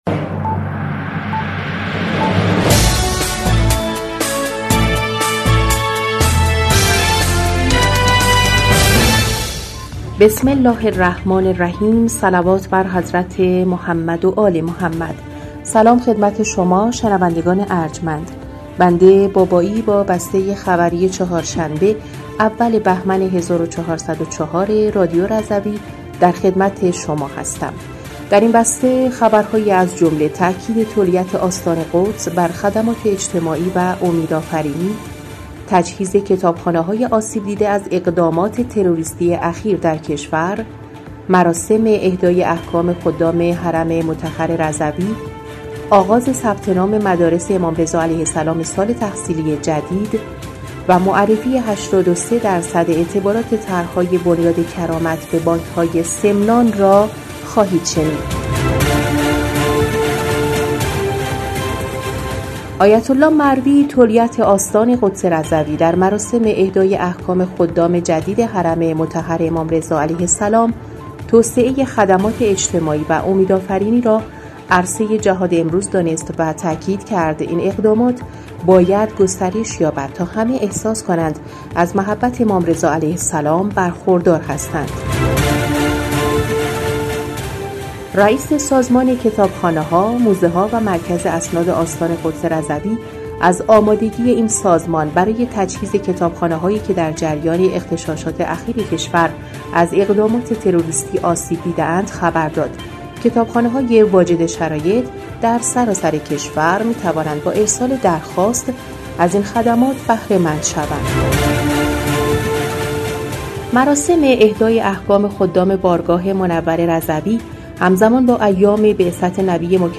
بسته خبری اول بهمن ۱۴۰۴ رادیو رضوی؛